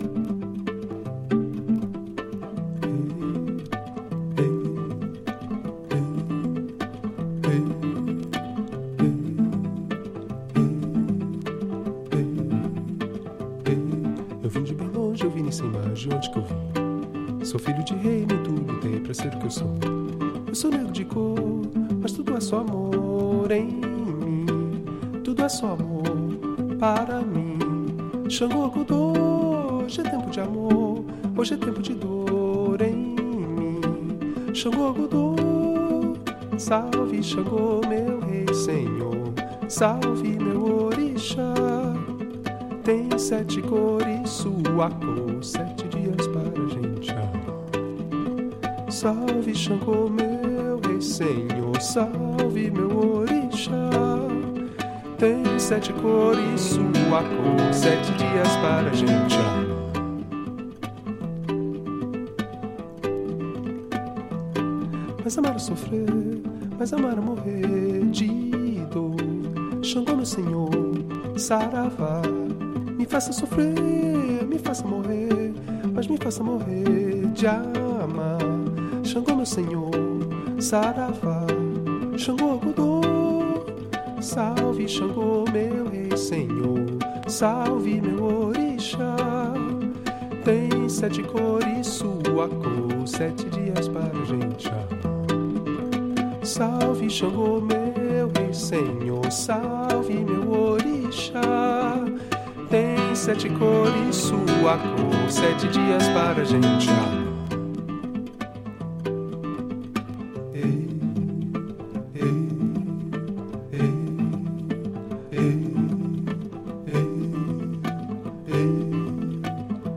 young Brasilian guitarist
trap drums and percussion
saxophone and flute
directly to a 1/2″ Ampex tape machine